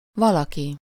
Ääntäminen
IPA: /ˈvɒlɒki/